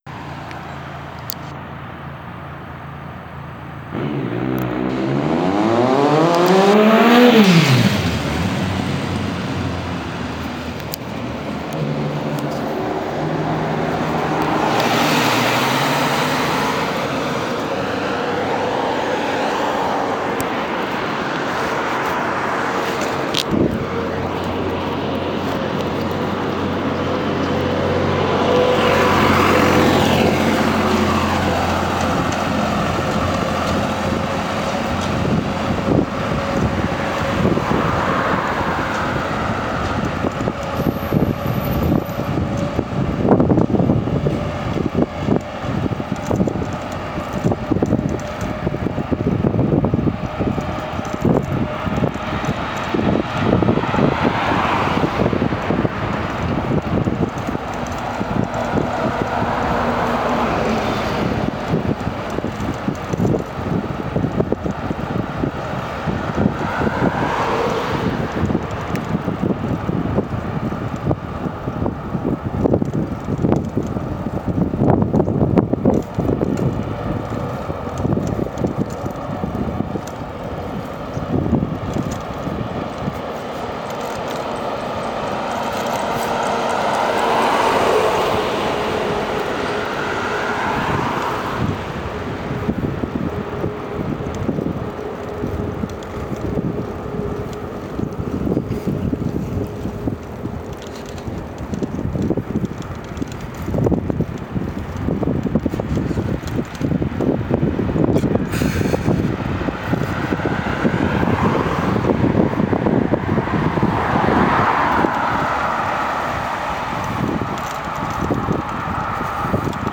2015-10-27 Herbst im Sonnenschein (Original Video Ton).flac